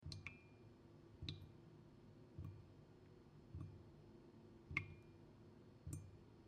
На этой странице собраны звуки слез — нежные, меланхоличные аудиозаписи падающих капель.
Здесь нет записей с плачущими людьми, только чистые звуки слезинок в высоком качестве.
Шепот слез падающих на стол